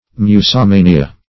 Musomania \Mu`so*ma"ni*a\, n.